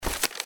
UI_OpenPage.mp3